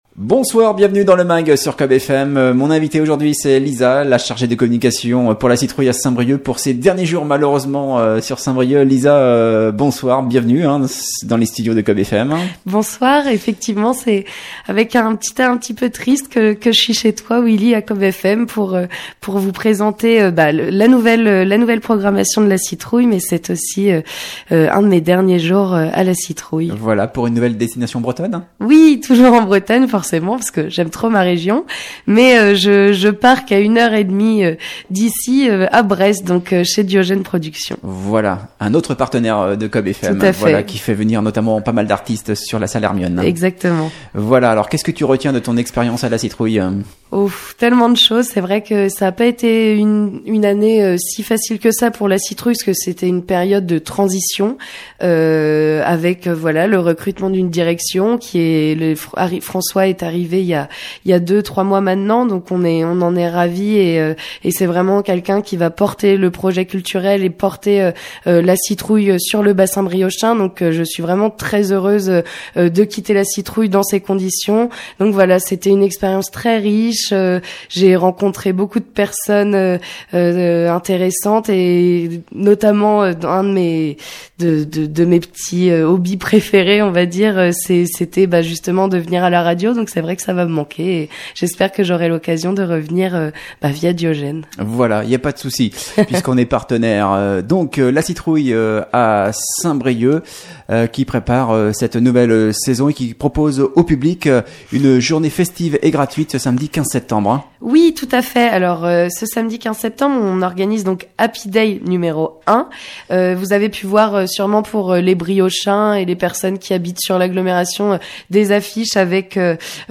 Invitée du Mag hier soir